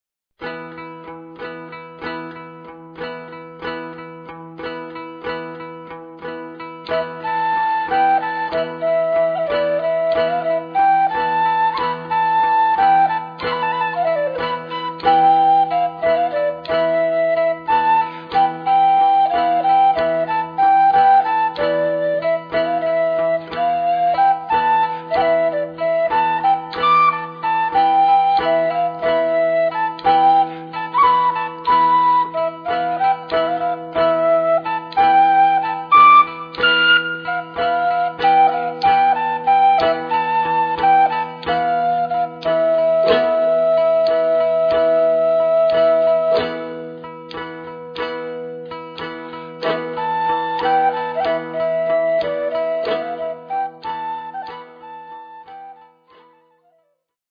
Classical
Historical